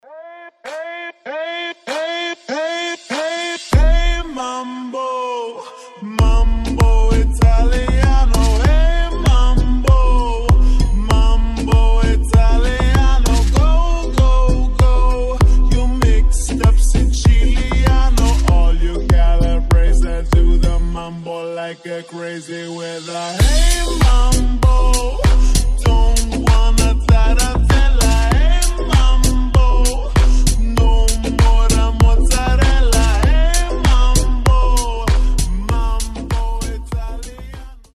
deep house
retromix
басы
медленные
качающие
ремиксы
Старый добрый рингтон в новом звучании.